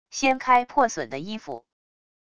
掀开破损的衣服wav音频